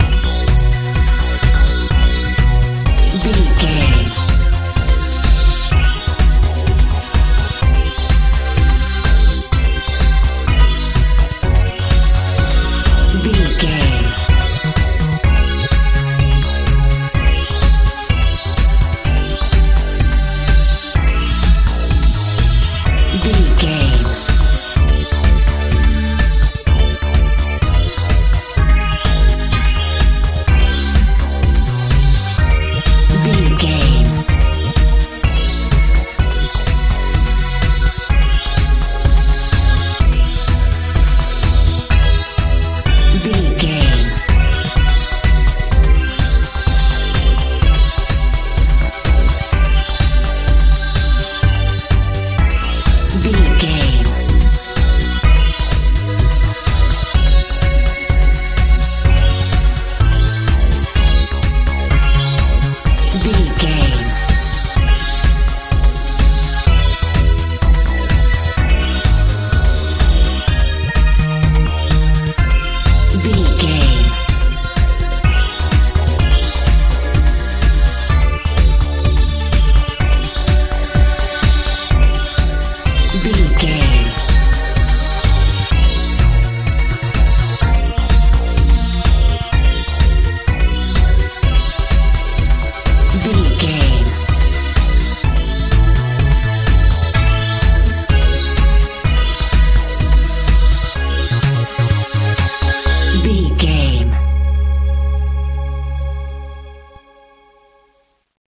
techno feel
Ionian/Major
intense
powerful
bass guitar
synthesiser
drums
strange